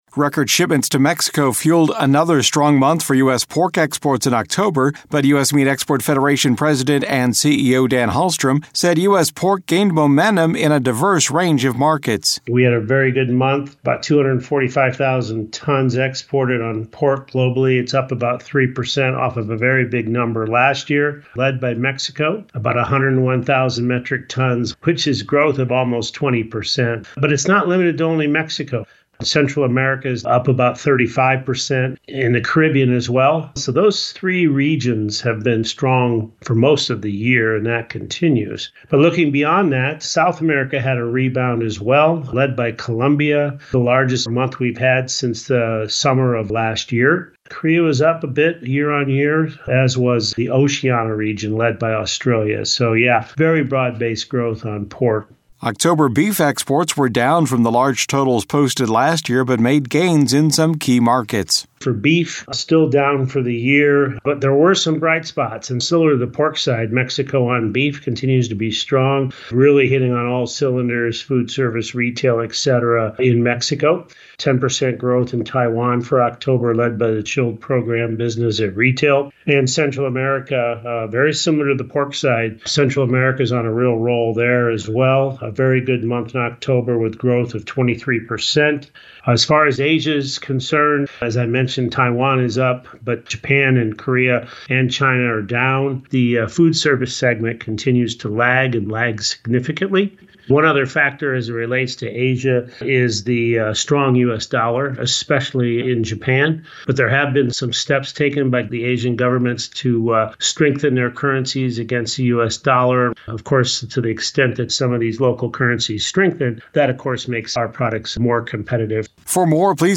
USMEF Audio Report. In addition to the record performance from Mexico, October pork exports to Central America were among the largest on record and shipments to Colombia were the largest since mid-2022.